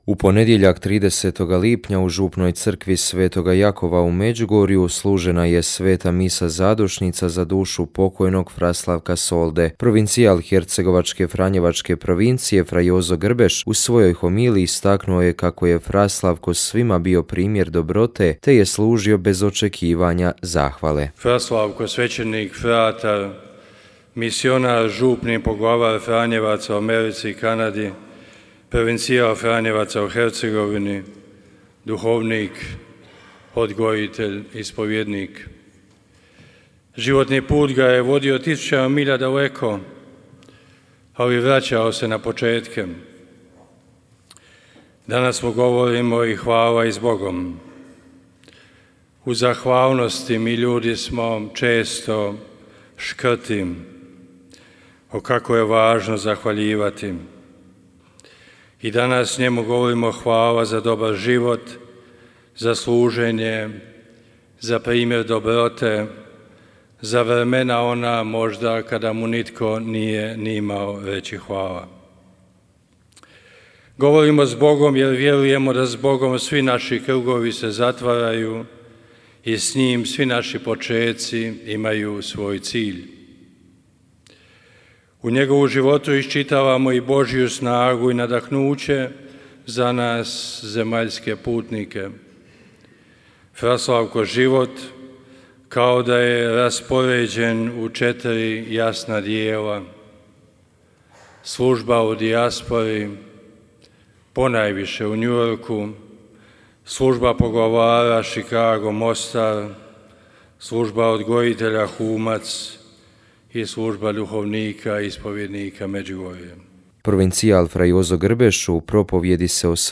Misa zadušnica i sprovod